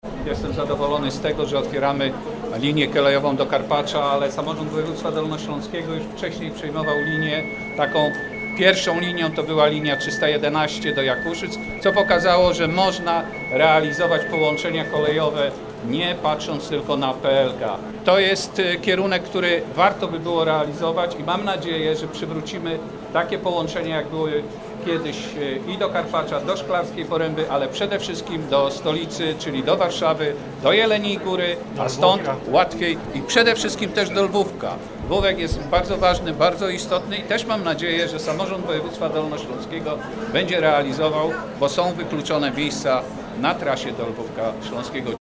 Mam nadzieję, że przywrócimy połączenia, które były kiedyś, przede wszystkim do Lwówka, aby przeciwdziałać wykluczeniu komunikacyjnemu mówi Jerzy Łużniak, prezydent Jeleniej Góry.